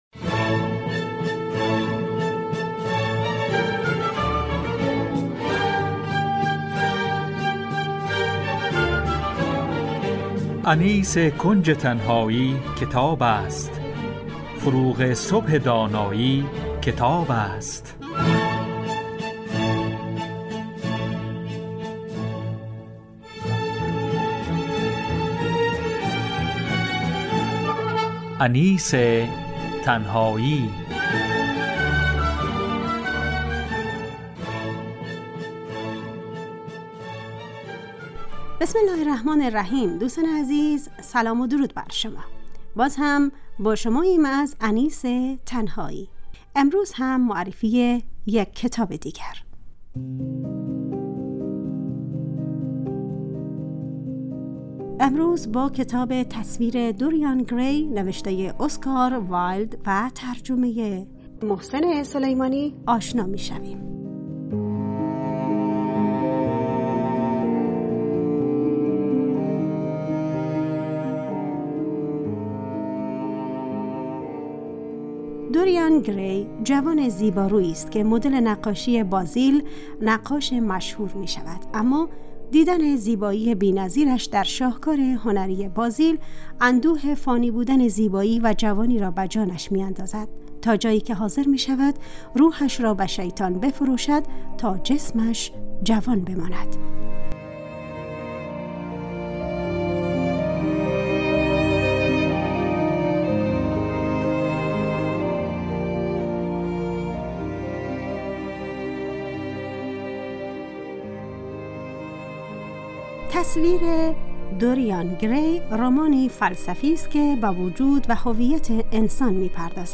معرفی کتاب : تصویر دوریان گری _ نوشته : اسکار وایلد _ گوینده و تهیه کننده